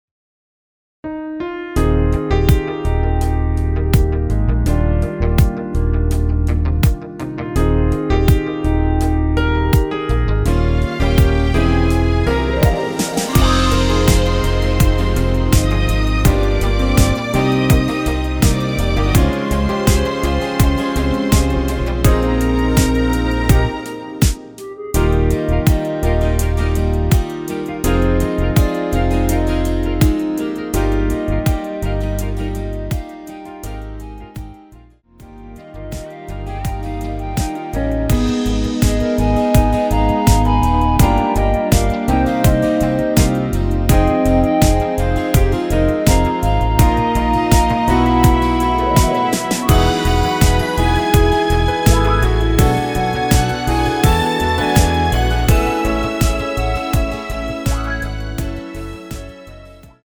원키 멜로디 포함된 MR입니다.
Eb
앞부분30초, 뒷부분30초씩 편집해서 올려 드리고 있습니다.
중간에 음이 끈어지고 다시 나오는 이유는